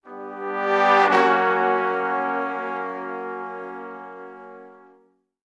Music Effect; Big Jazz Band Brass Swells.